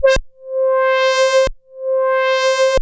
Roland A C5.wav